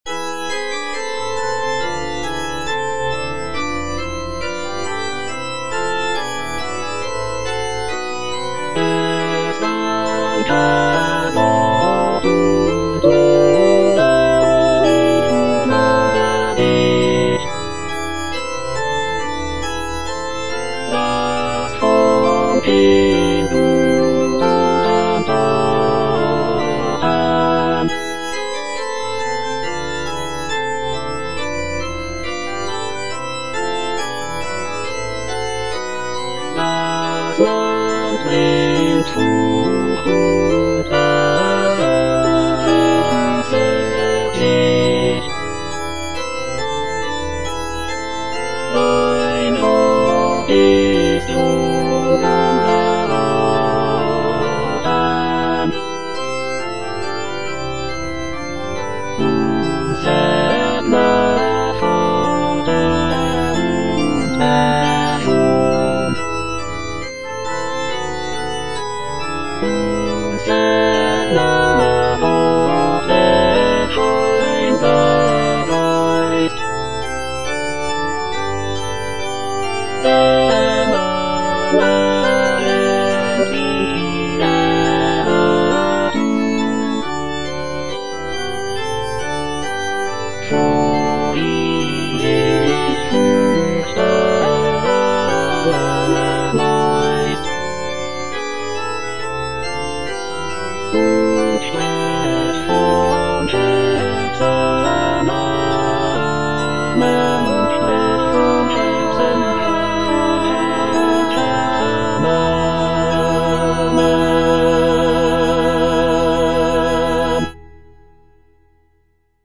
Cantata
(All voices)